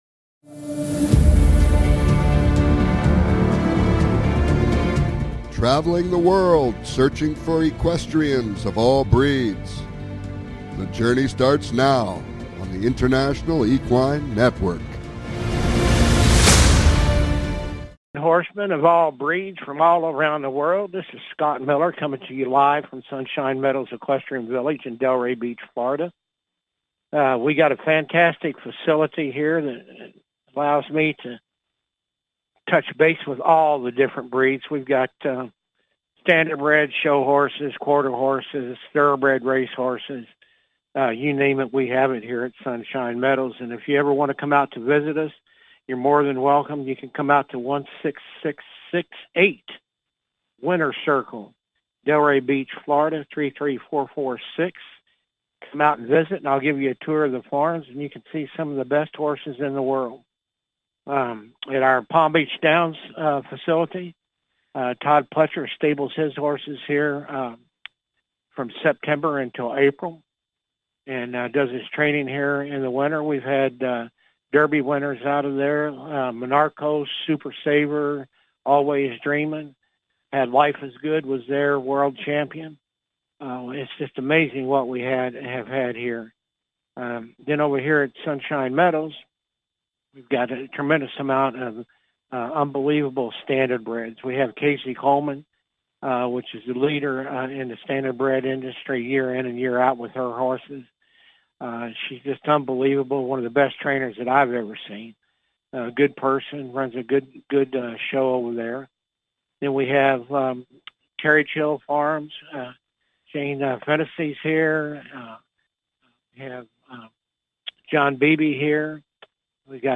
Talk Show Episode
Calls-ins are encouraged!